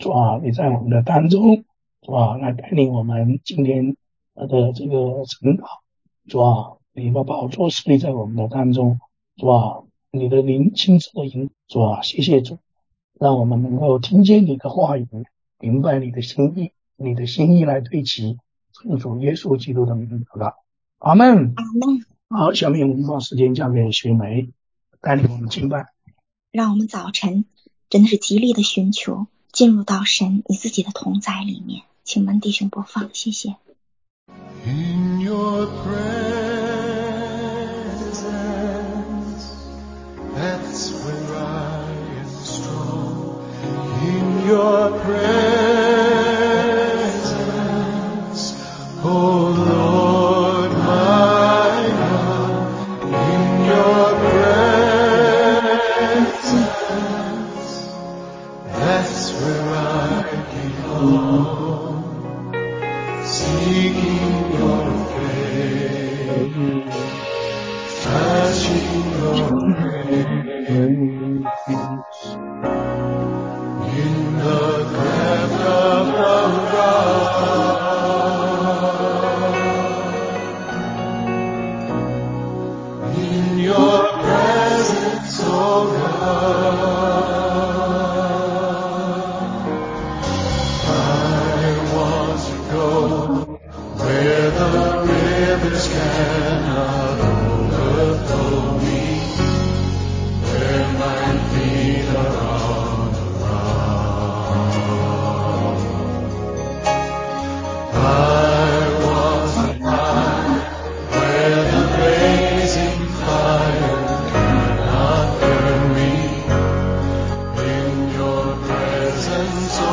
晨祷